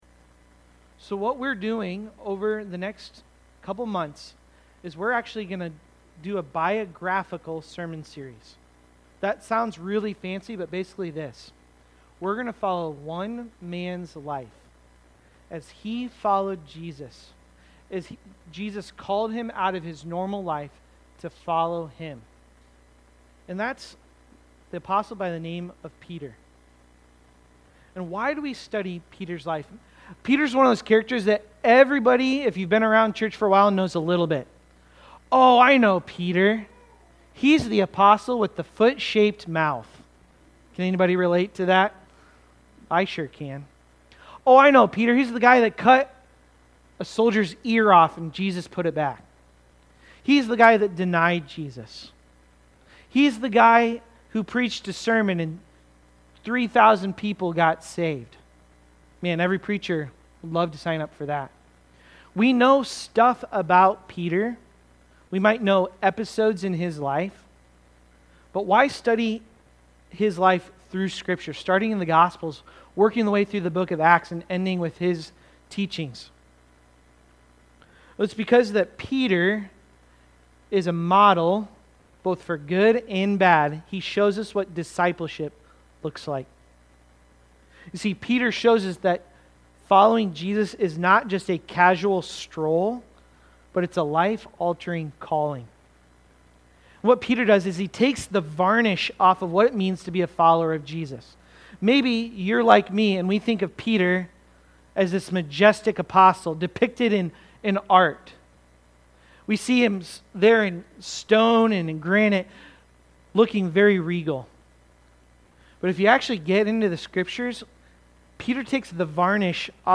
Fisherman-1-Sermon.mp3